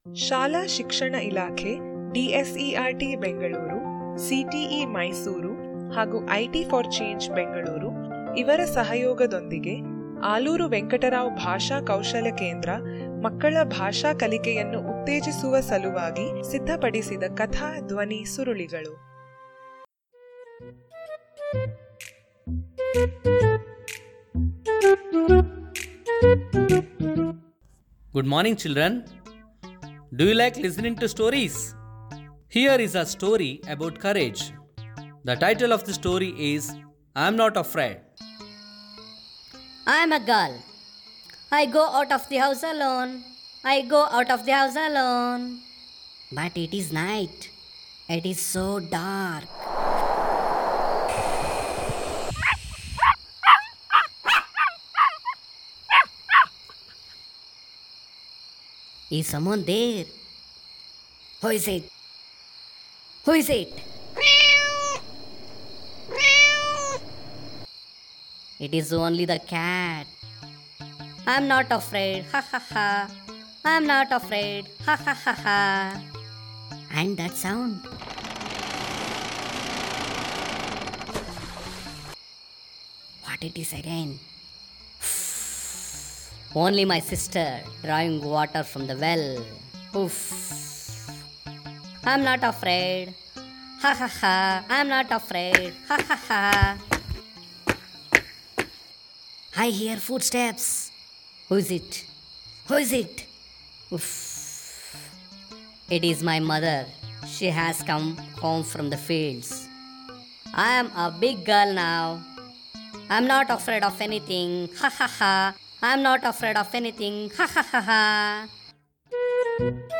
I Am Not Afraid - AUDIO STORY ACTIVITY PAGE